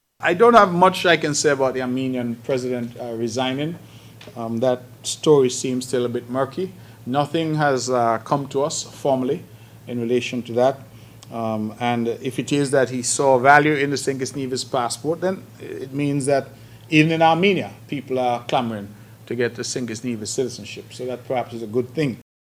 Those were comments from Federal Minister of Foreign Affairs, the Hon. Mark Brantley on the recent resignation of the Armenia President, Armen Sarkissian, for holding a St. Kitts and Nevis Passport.